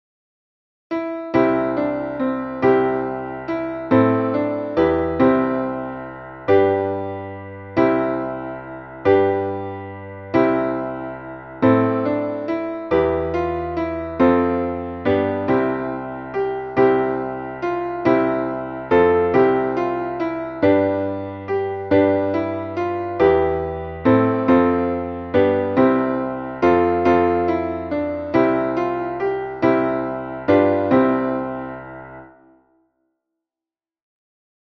Traditionelles Wiegen-/ Weihnachtslied